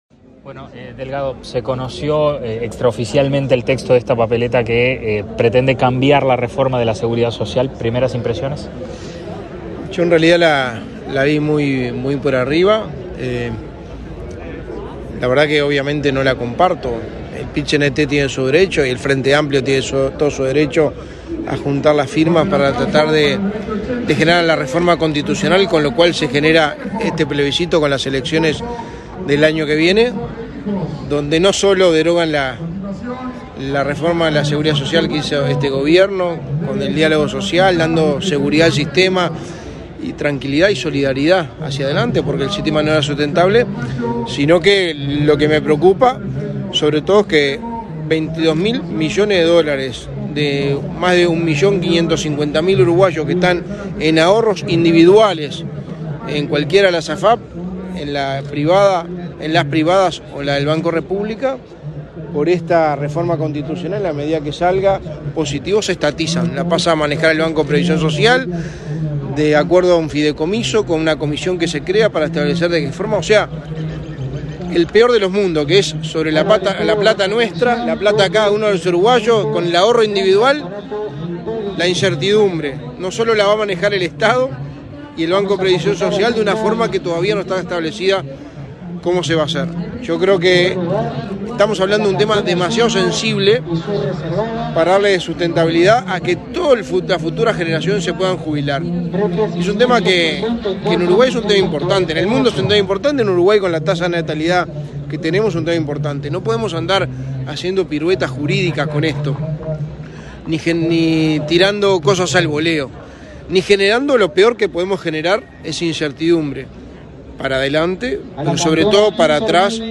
Declaraciones a la prensa del secretario de la Presidencia, Álvaro Delgado
El secretario de la Presidencia, Álvaro Delgado, participó, este 14 de setiembre, en la entrega de los premios a bovinos durante la séptima jornada de